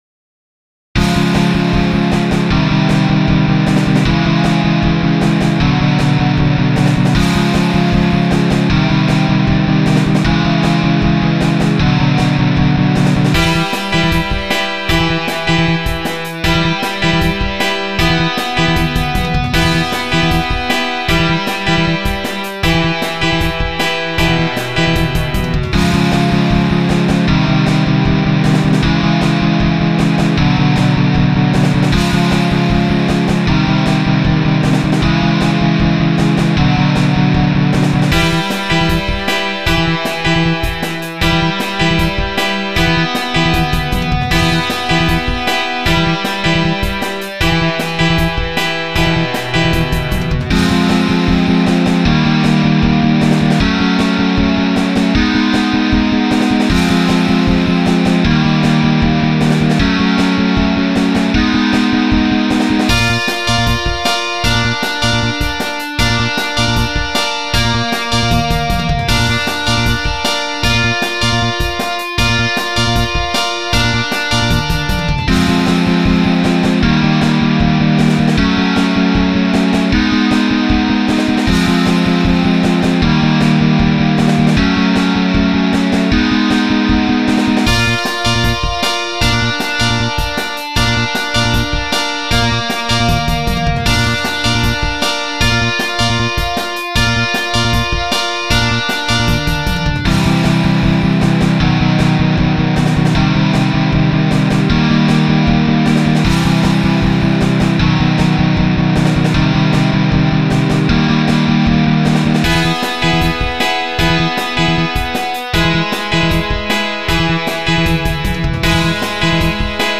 ジャンル：インストゥルメンタル
私にしては、珍しくロックぽいのです